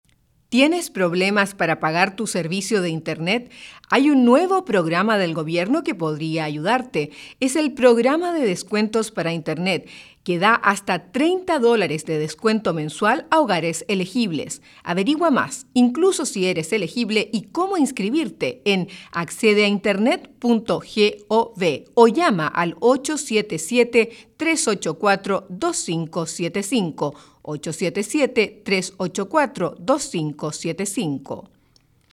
• PSA de audio (estilo informal) -
fcc-acp-psa-scripts-spanish-30sec-informal.mp3